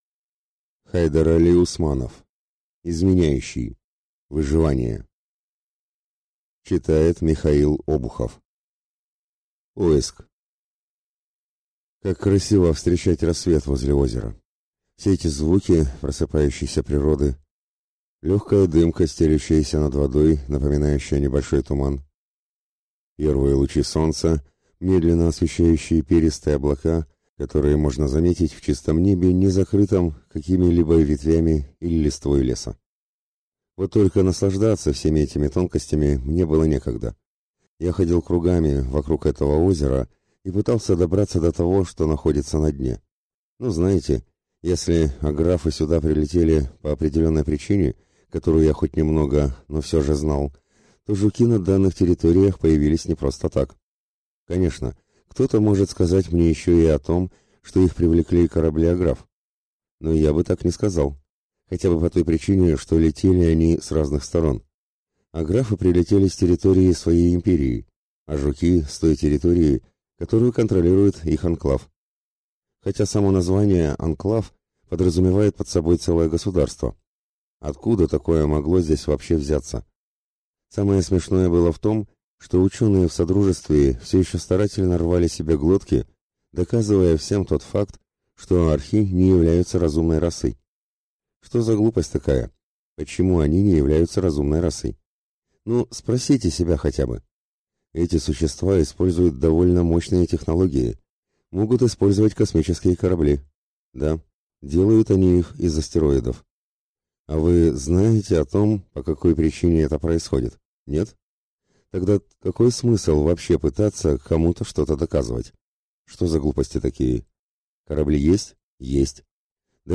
Аудиокнига Изменяющий. Выживание | Библиотека аудиокниг